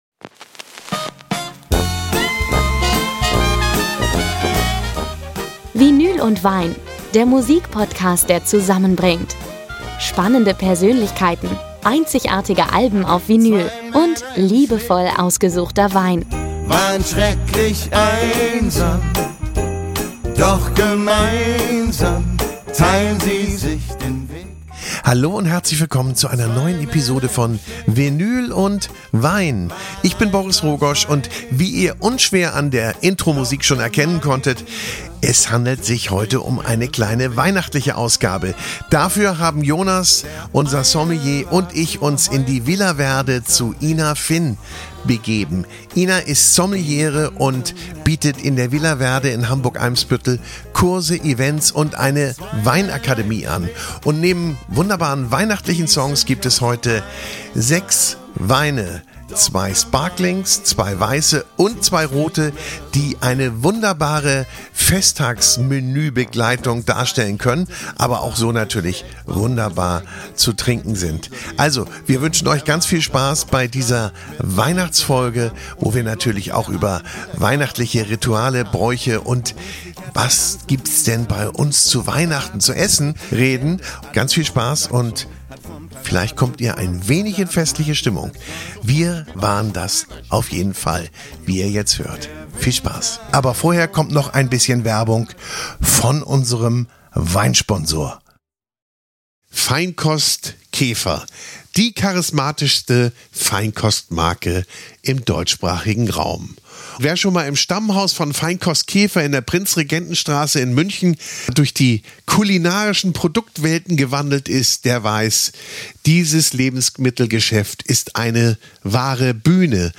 Außerdem gibt’s heiße Tipps, welche Weine perfekt zu Gans, Rotkohl oder Desserts passen – und worauf man beim Einkauf achten sollte. Die Runde plaudert über weihnachtliche Gerüche, Kindheitserinnerungen und wie viel Deko zu viel ist. Musikalisch wird’s ebenfalls festlich, aber mit Stil.